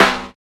118 SNARE 2.wav